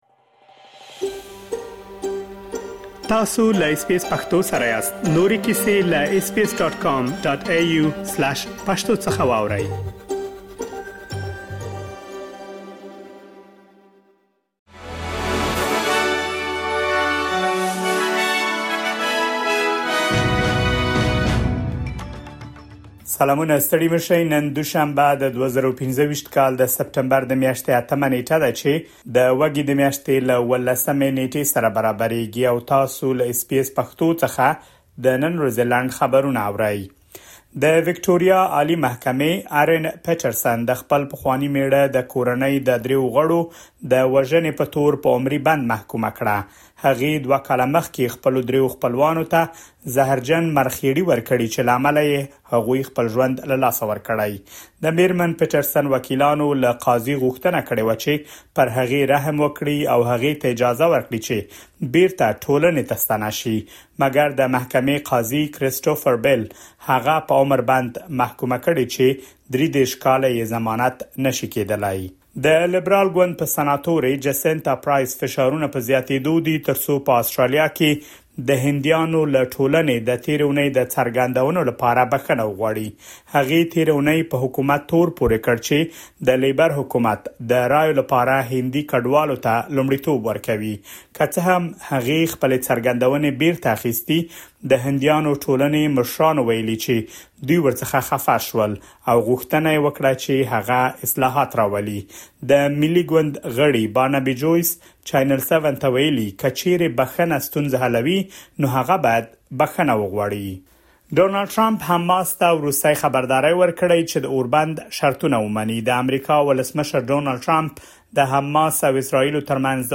د اس بي اس پښتو د نن ورځې لنډ خبرونه |۸ سپټمبر ۲۰۲۵